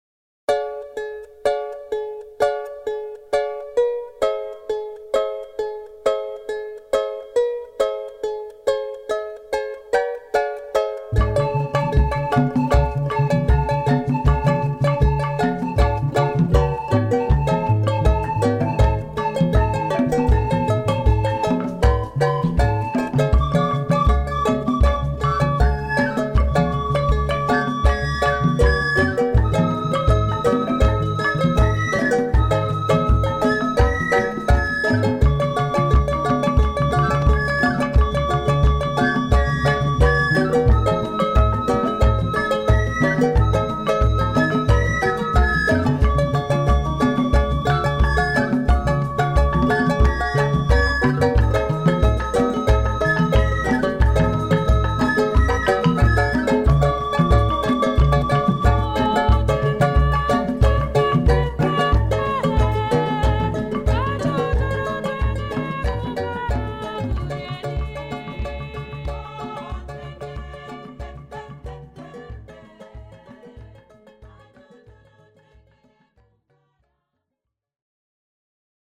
• Melodies and rhythms from across Africa
Hailing from various parts of Surrey and Greater London, this energetic drumming and dancing troupe stage fascinating displays of traditional African rhythms, songs and dances.